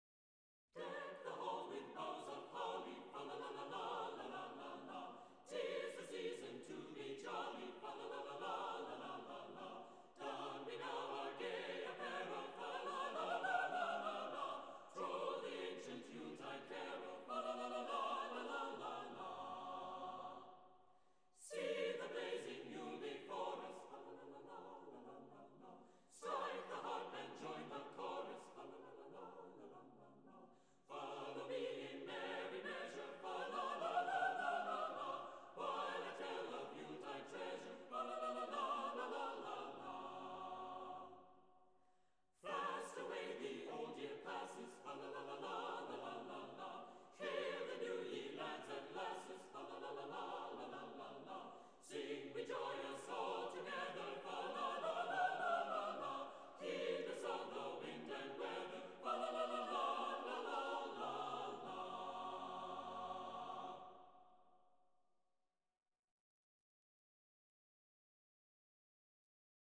The Portland Carolers is a group of four singers: two men and two women.
In the true, spirit of the day, all of the music of the Portland Carolers is unaccompanied, yet sung in four-part harmony.